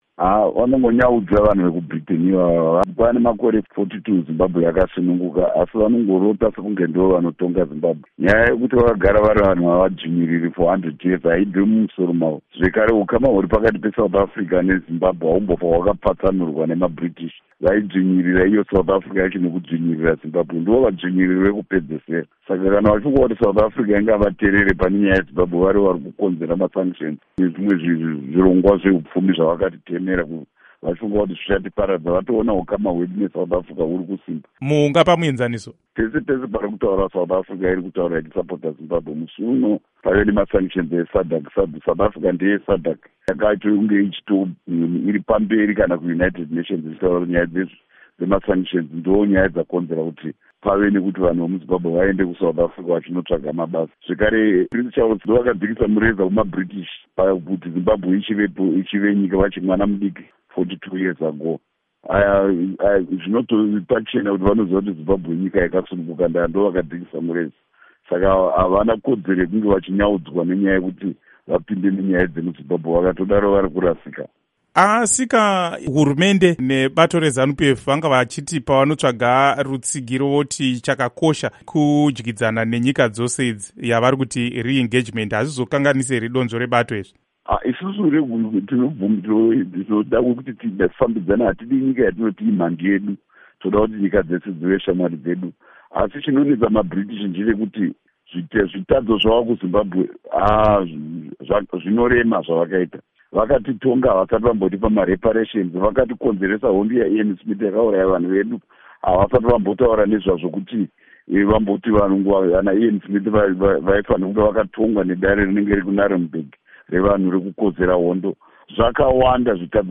Hurukuro naVaChris Mutsvangwa